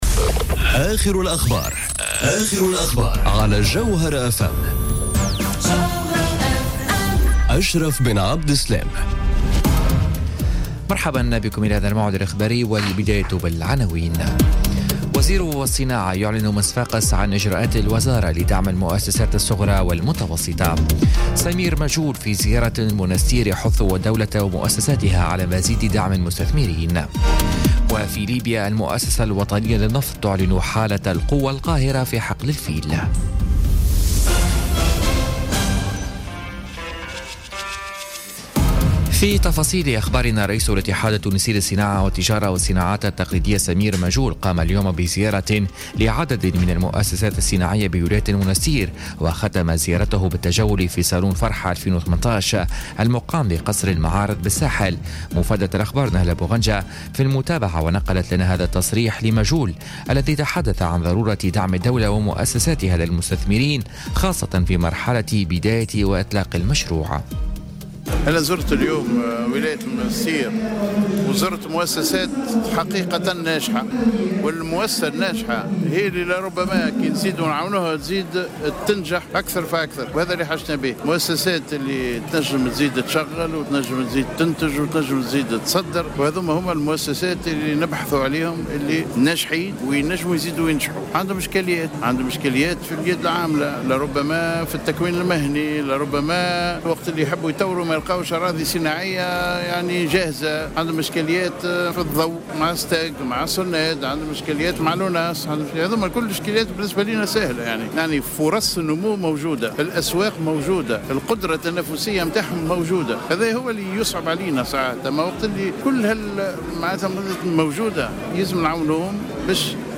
نشرة أخبار السابعة مساء ليوم السبت 24 فيفري 2018